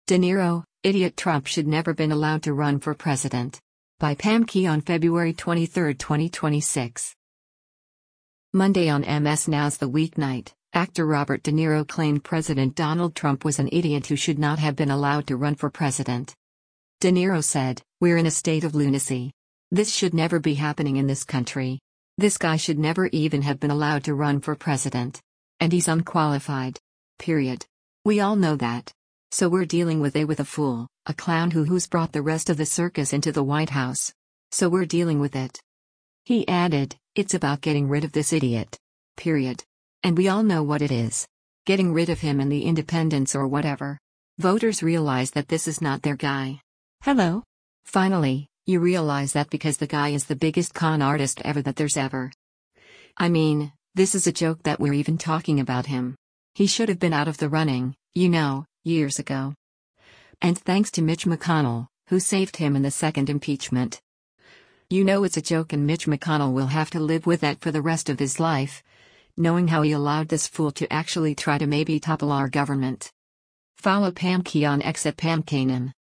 Monday on MS NOW’s “The Weeknight,” actor Robert De Niro claimed President Donald Trump was an “idiot” who should not have been allowed to run for president.